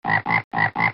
rana
Sonido FX 32 de 42
rana.mp3